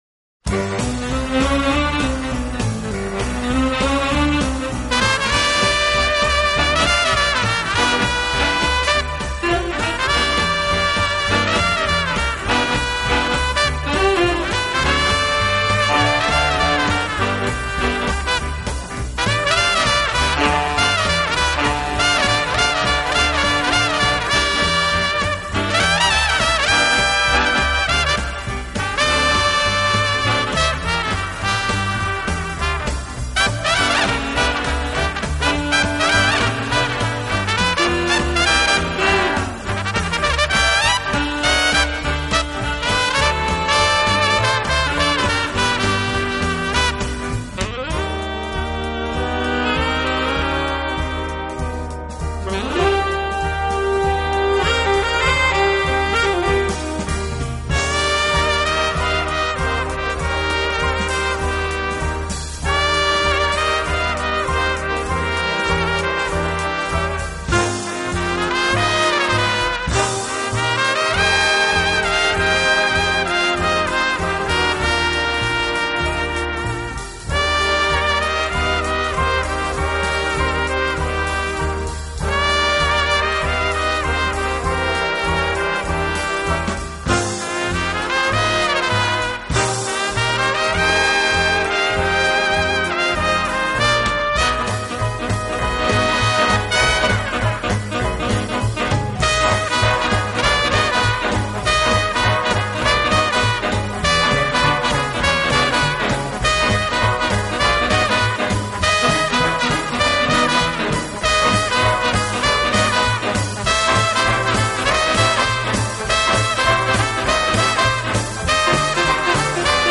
【轻音乐专辑】
轻快、柔和、优美，带有浓郁的爵士风味。
代感的，乐而不狂，热而不躁，这支以萨克斯管为主体的乐队以鲜明的特征